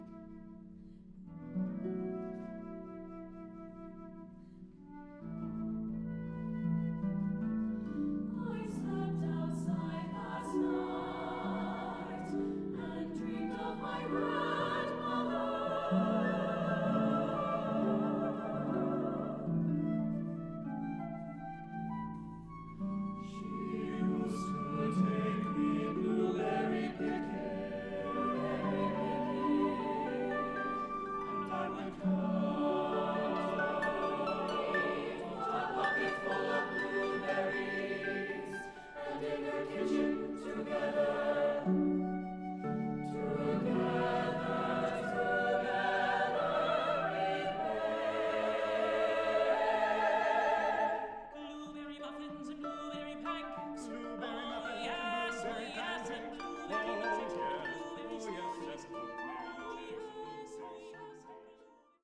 Division: 2024 Collegiate Division, Vocal Choir